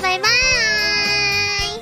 Worms speechbanks
Byebye.wav